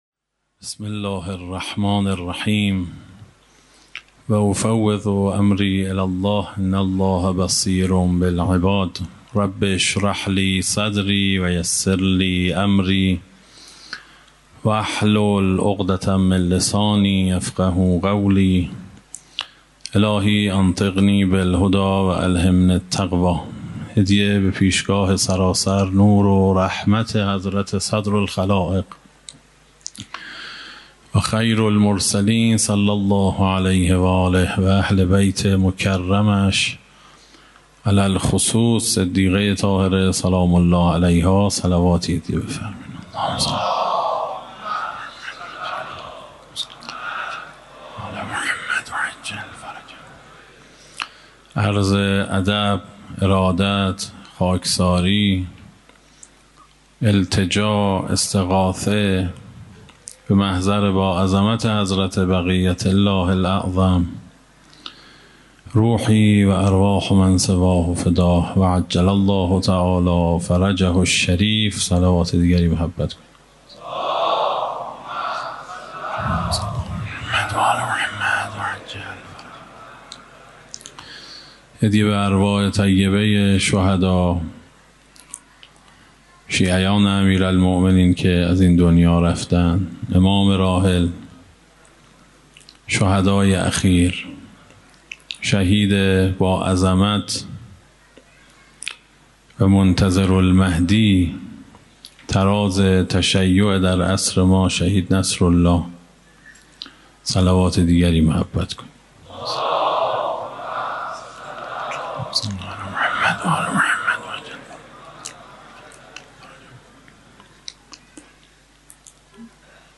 در آستان مقدس امامزاده قاضی الصابر علیه السلام
سخنرانی